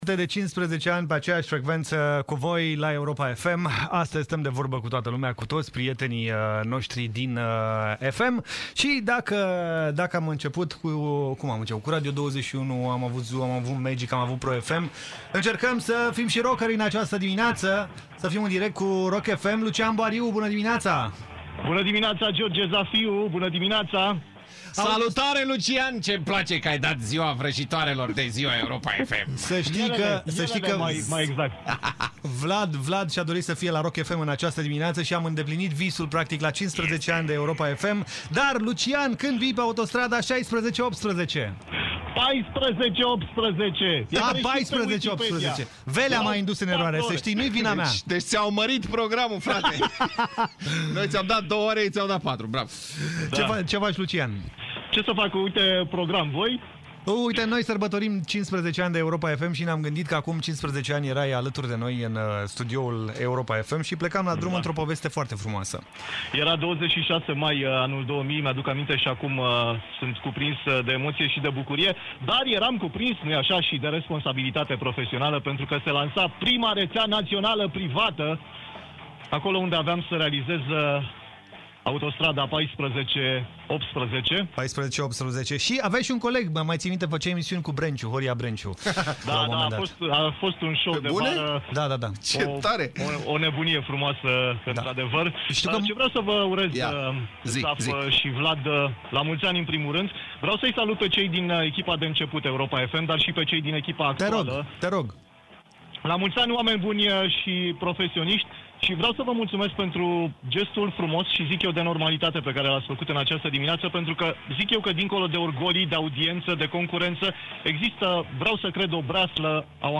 La aniversarea de 15 ani, familia Europa FM a primit urări și mesaje de la colegii altor posturi de radio.